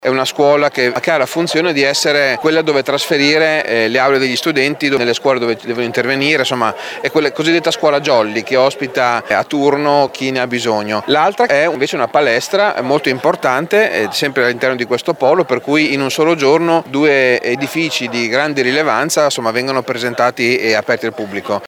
Stamattina è stata anche la giornata del taglio del nastro al Polo Leonardo per la nuova scuola Jolly e la palestra del Selmi e del Corni. Luigi Zironi, consigliere provinciale delegato alla scuola: